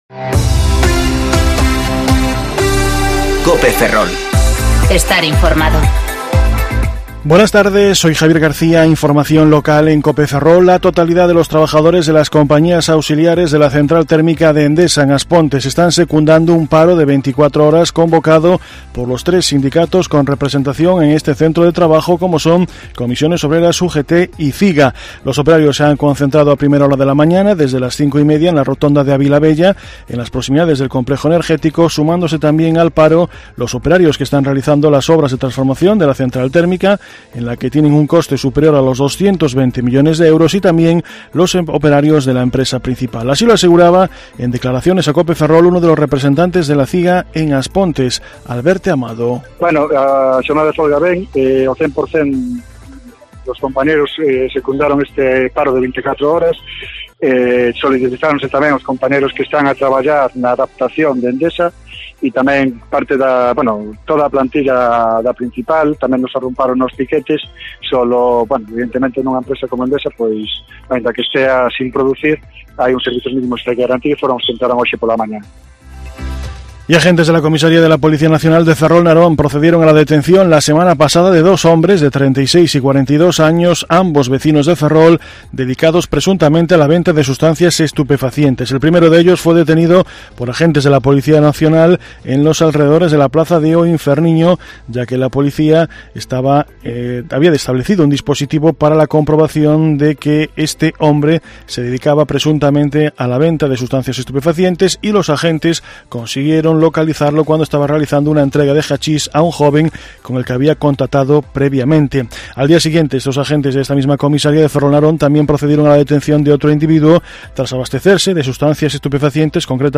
Informativo Mediodía Cope Ferrol 19/9/2019 (De 14.20 a 14.30 horas)